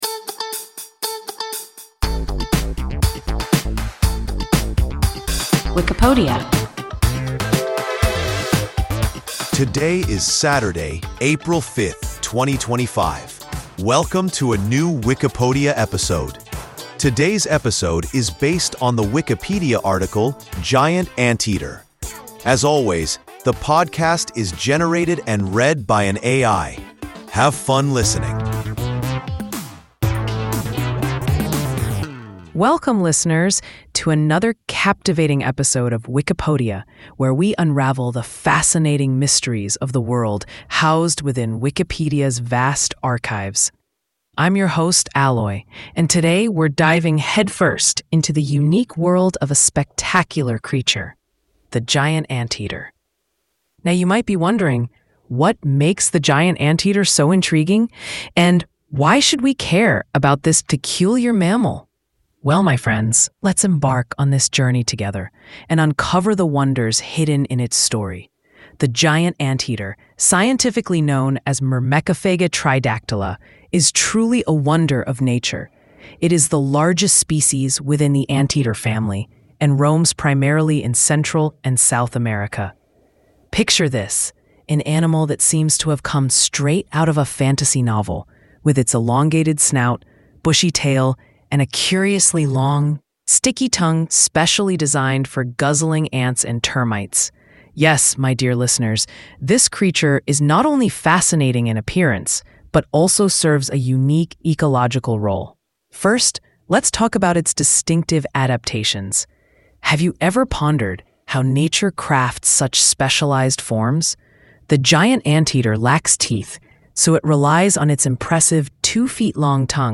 Giant anteater – WIKIPODIA – ein KI Podcast